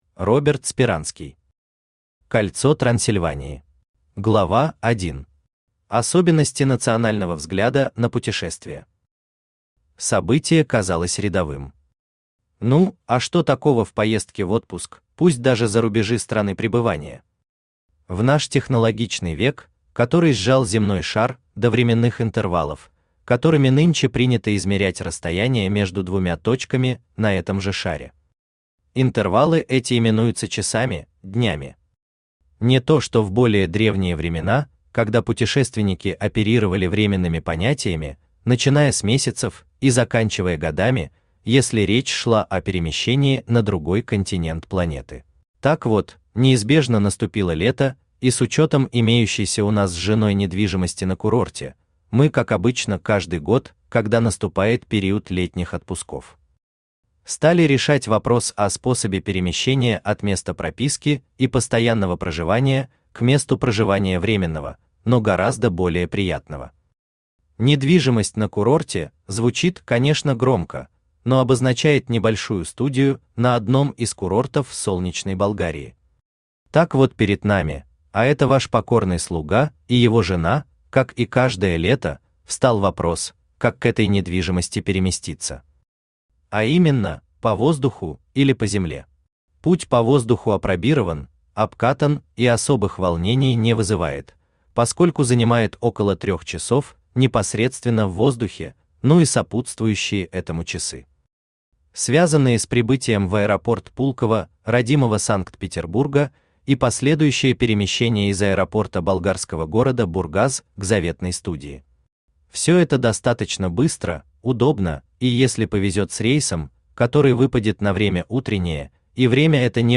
Аудиокнига Кольцо Трансильвании | Библиотека аудиокниг
Aудиокнига Кольцо Трансильвании Автор Роберт Юрьевич Сперанский Читает аудиокнигу Авточтец ЛитРес.